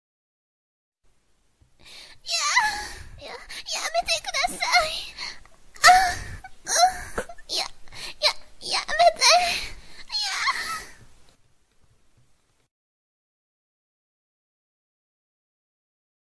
Categoria: Sons de Anime
Yamete kudasai significa "pare" em japonês e, gramaticalmente, é uma forma educada de pedir para alguém parar. Essa expressão japonesa é popular entre muitas pessoas e virou um meme de som muito divertido.
yamete-kudasai-sound-effect-pt-www_tiengdong_com.mp3